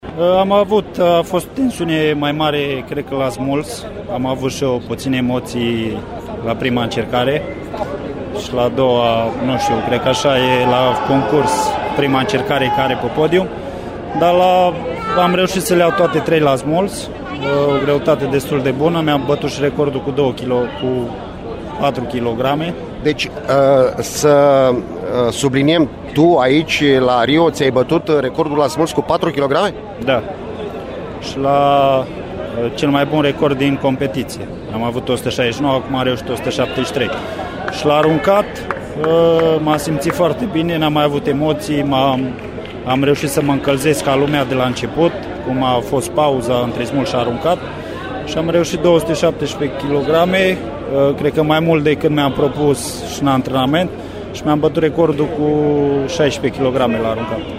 Gabriel Sîncrăian a vorbit despre performanța sa imediat după concurs: